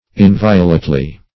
inviolately - definition of inviolately - synonyms, pronunciation, spelling from Free Dictionary Search Result for " inviolately" : The Collaborative International Dictionary of English v.0.48: Inviolately \In*vi"o*late*ly\, adv. In an inviolate manner.